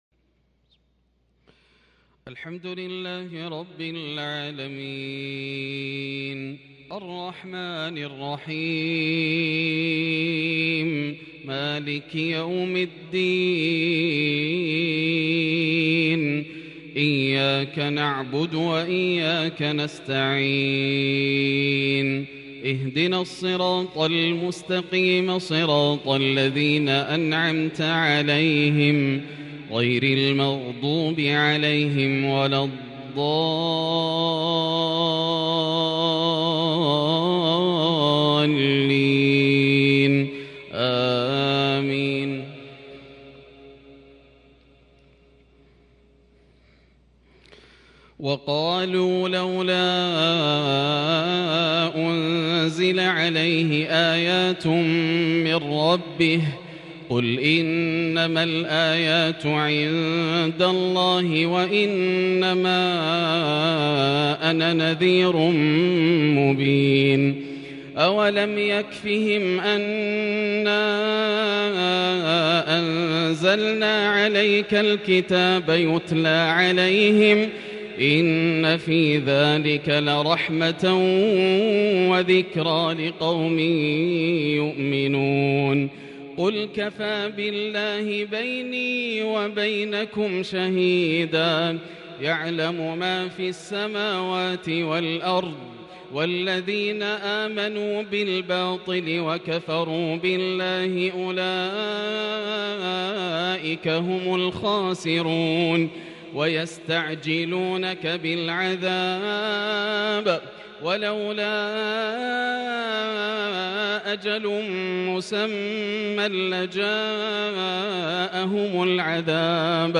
عشاء الجمعة 2-6-1442هـ من سورة العنكبوت |Isha Prayer from Surat Al-Ankaboot 15/1/2021 > 1442 🕋 > الفروض - تلاوات الحرمين